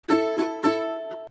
под балалайку